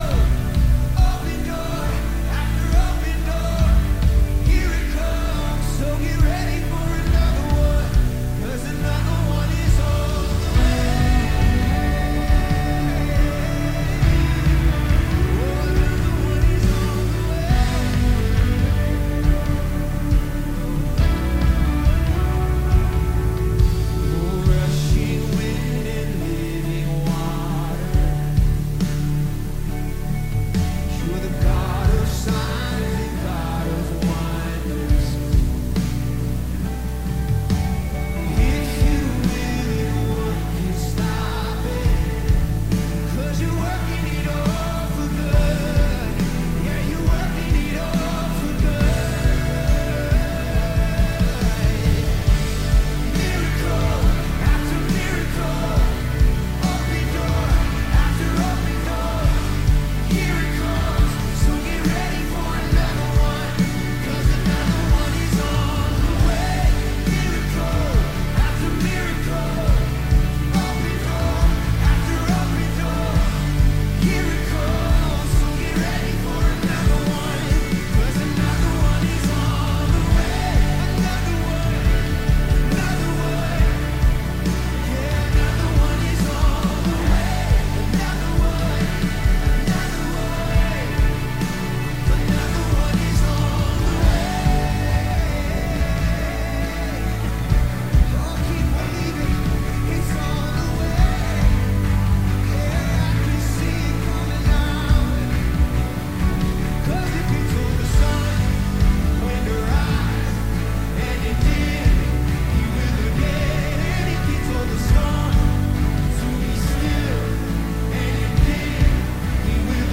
Teaching on Bridging the Gap between Generations and what they need in their spiritual walk with The Lord.